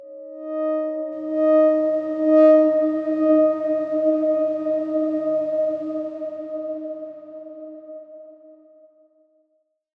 X_Darkswarm-D#4-mf.wav